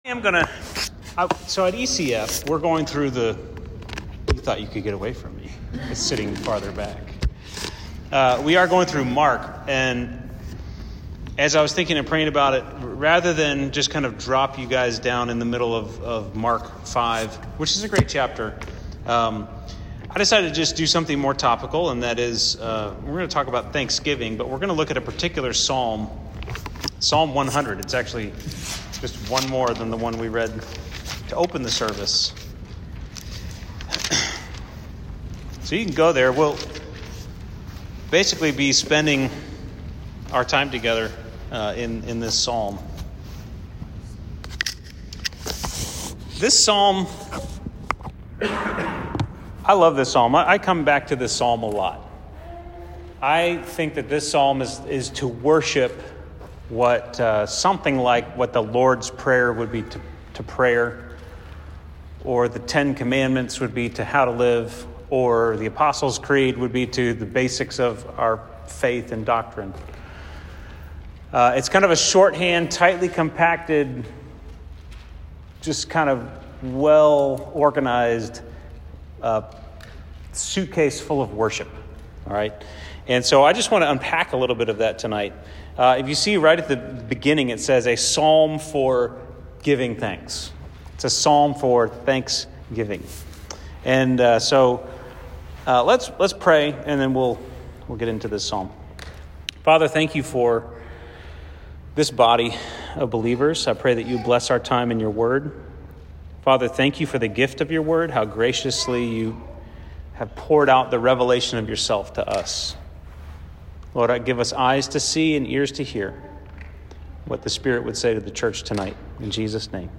Sermon 11/29: Psalm 100